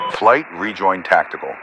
Added .ogg files for new radio messages
Radio-playerWingmanRejoin5.ogg